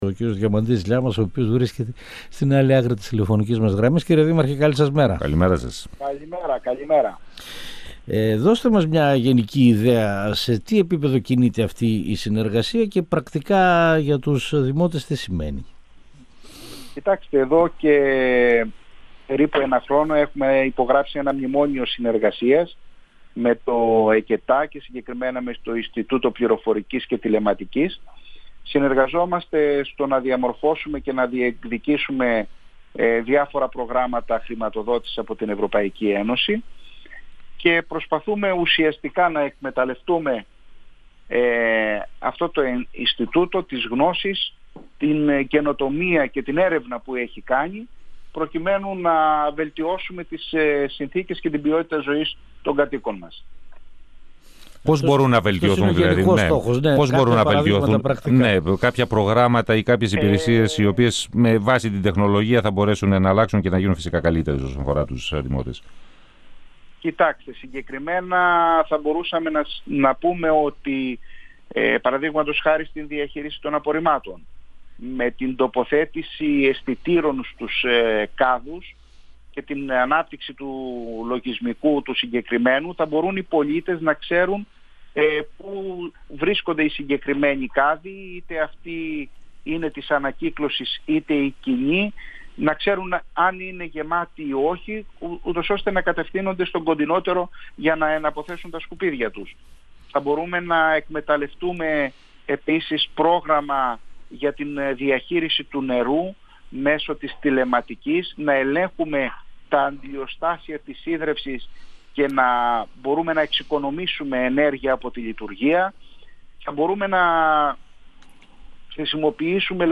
Ο δήμαρχος Βόλβης, Διαμαντής Λιάμας, στον 102FM του Ρ.Σ.Μ. της ΕΡΤ3
Συνέντευξη